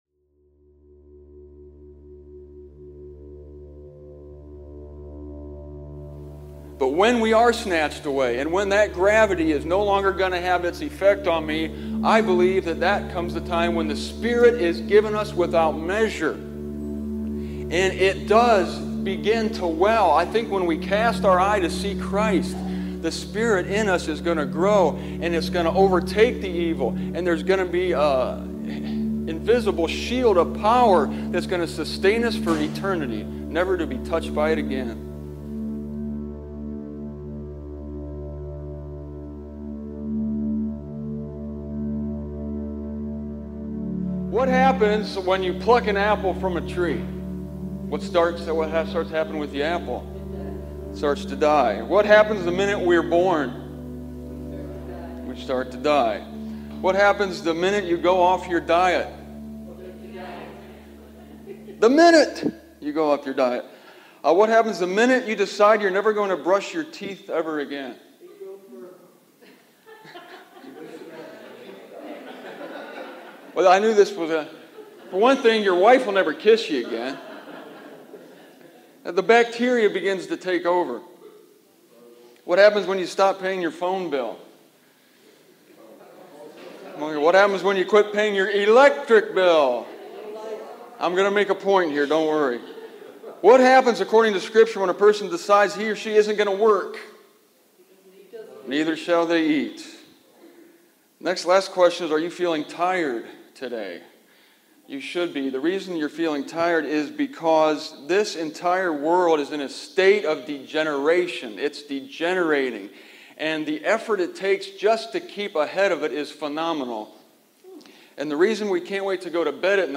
When I gave this message in Willard, OH, in 2002, I had decided (based on Scripture, of course) that EVIL was the default setting of the world, and that God adds just enough good to keep us going.